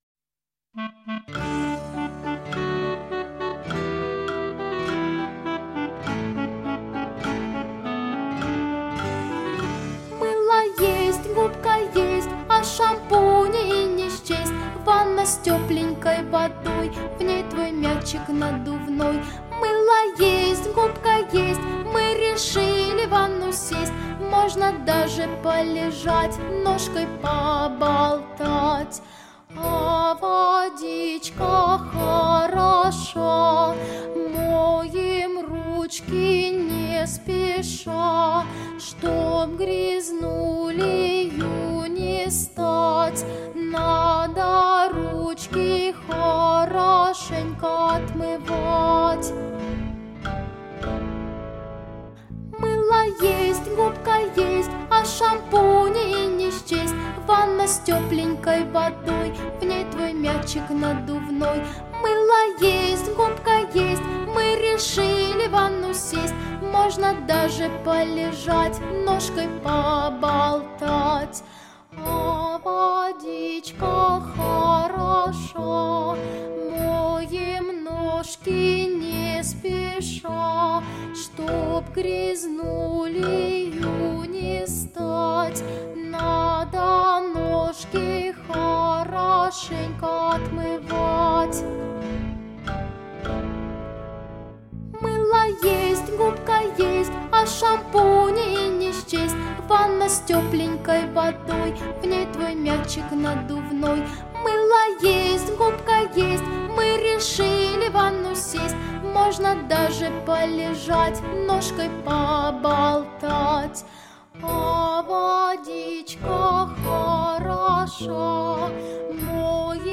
Детские песни и музыка